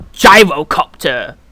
pugyrocoptervoice.wav